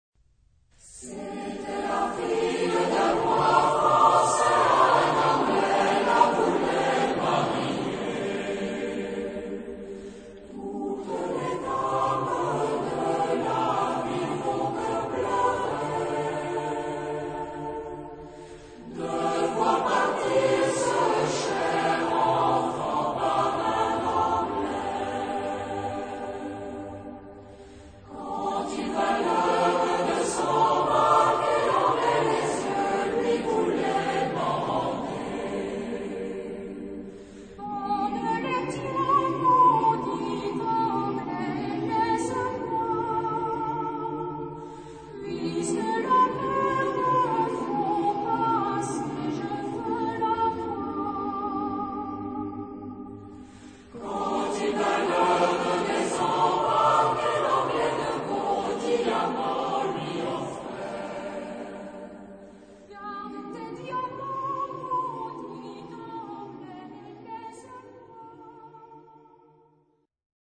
Genre-Style-Form: Popular ; Secular
Mood of the piece: lively
Type of Choir: SATB  (4 mixed voices )
Tonality: G dorian
Number of verses: 7
Origin: Lyonnais (F)